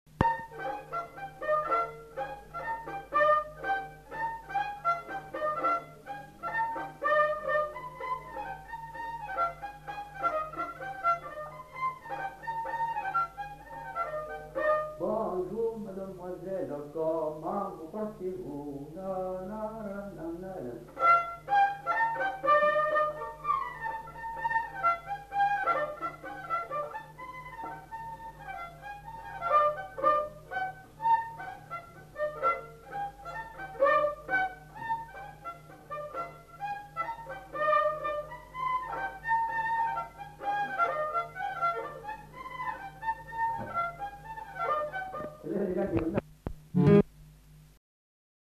Lieu : Casteljaloux
Genre : morceau instrumental
Instrument de musique : violon
Danse : polka piquée
Notes consultables : L'interprète chante l'incipit du chant.